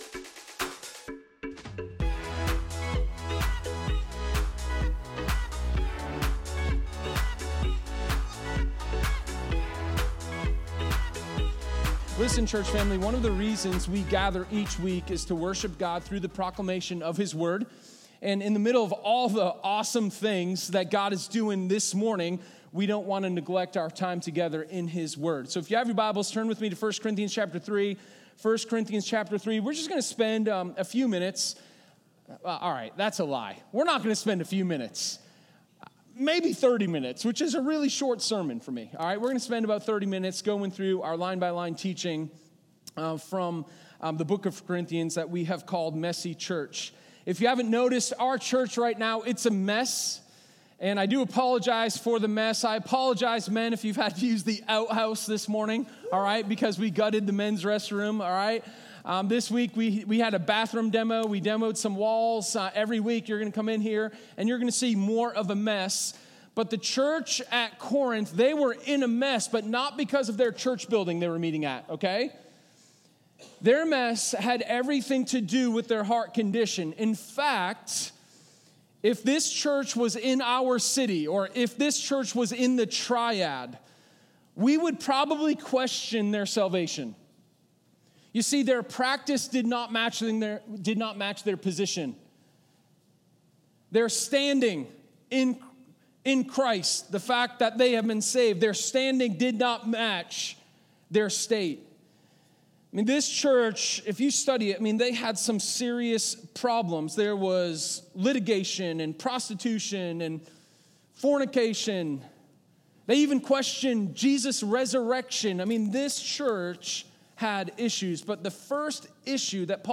Sermon11_14_Commands-for-Combat.m4a